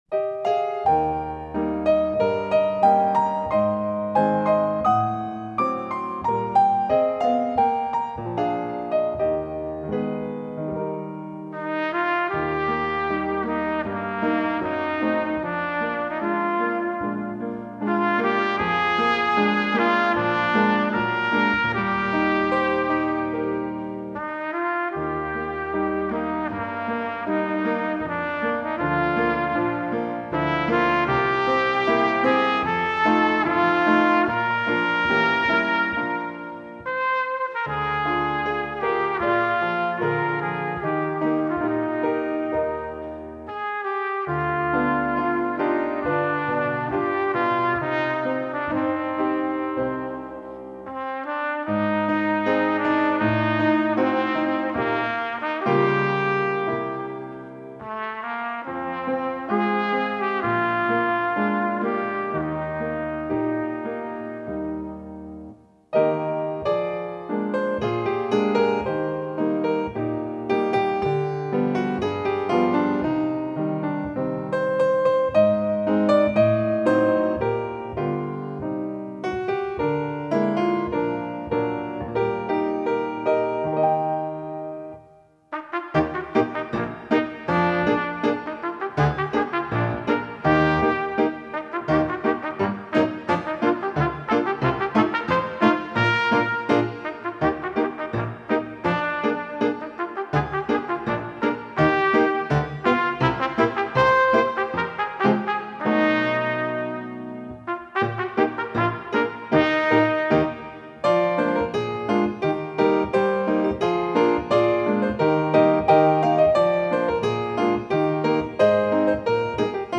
Antares Trumpet Solo with Piano Accompaniment – Performance Tempo Antares Trumpet Solo with Piano Accompaniment – Performance Tempo Antares Trumpet Solo Piano Only – Performance Tempo Antares Trumpet Solo Piano Only – Performance Tempo
04-Antares-with-trumpet.mp3